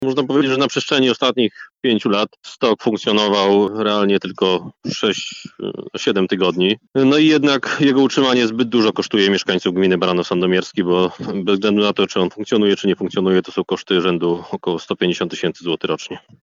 Mówi burmistrz miasta i gminy Baranów Sandomierski Marek Mazur.